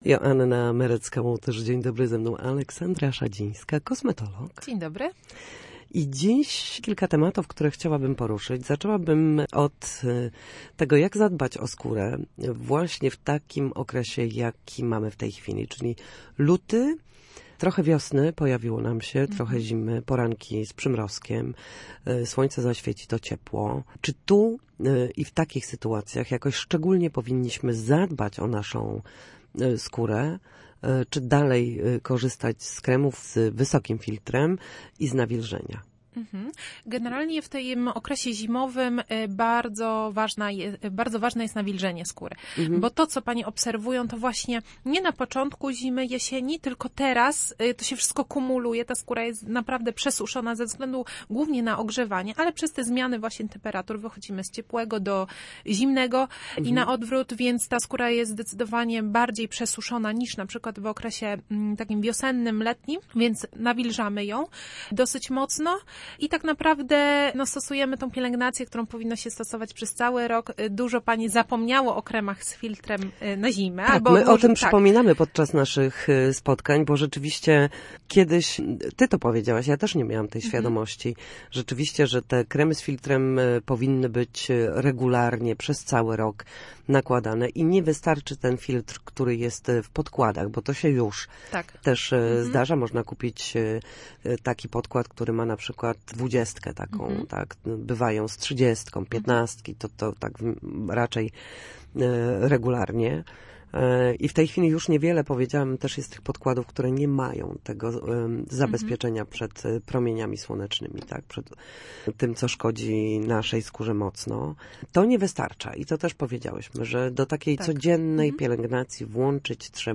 W każdą środę po godzinie 13.00 na antenie Studia Słupsk przedstawiamy sposoby na powrót do formy po chorobach i urazach.